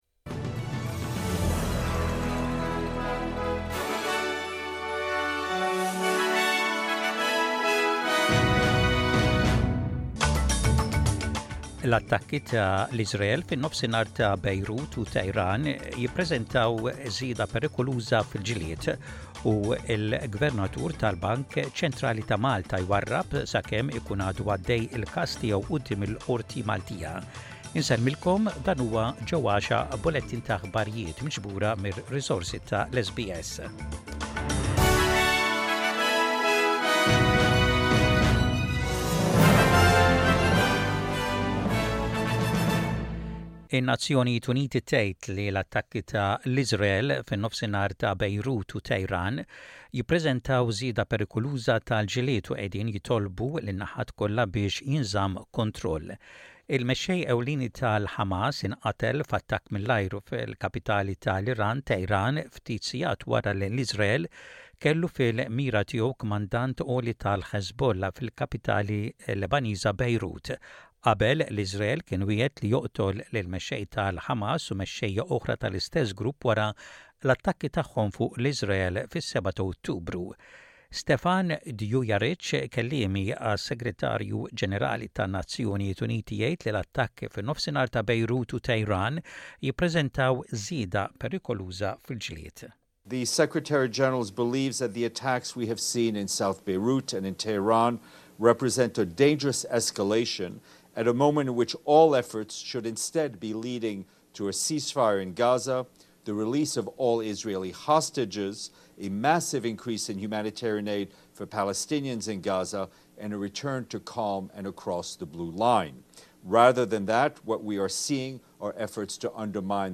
SBS Radio | Aħbarijiet bil-Malti: 02.08.24